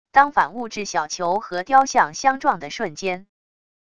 当反物质小球和雕像相撞的瞬间wav音频